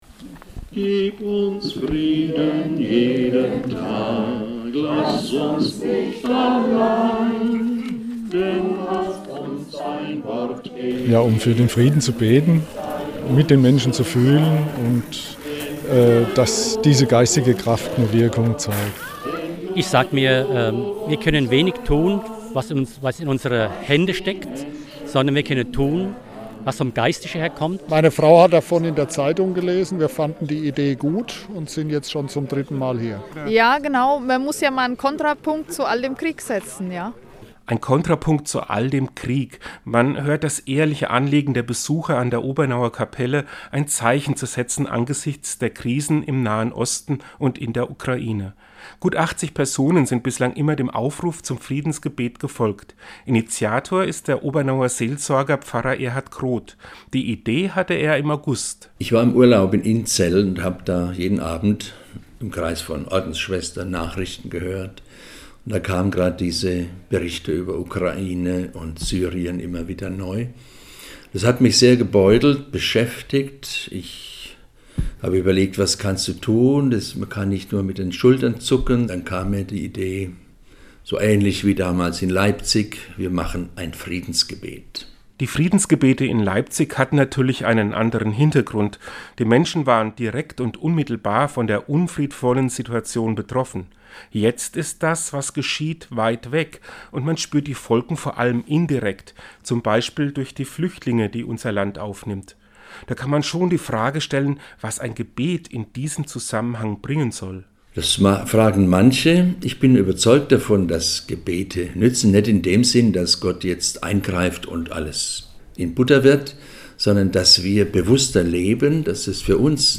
Einen Radiointerview zum Thema finden Sie hier!